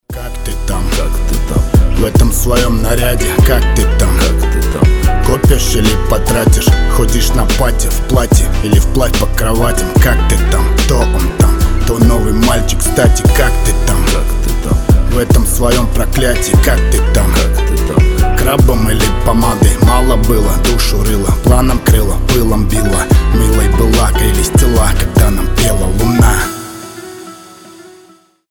рэп
грустные